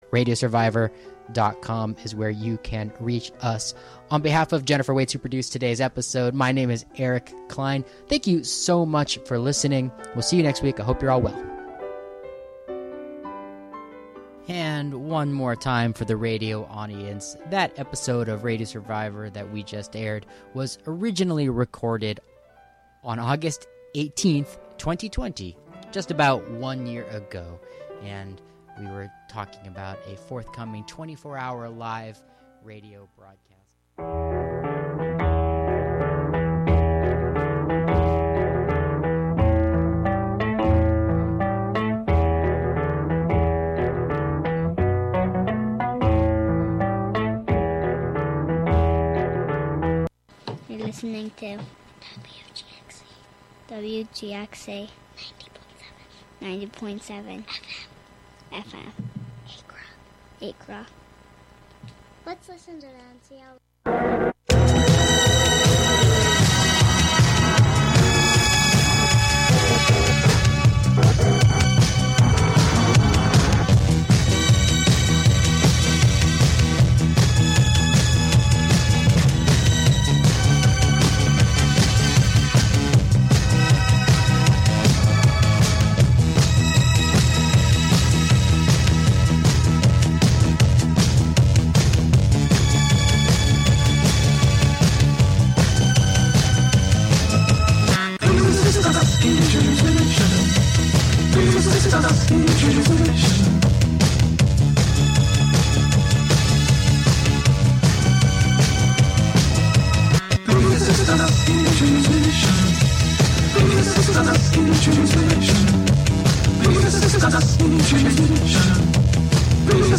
Contributions from many WGXC programmers.
Every Saturday in the 9 a.m. hour, WGXC airs the latest episode of the radio drama "I Have Seen Niagara."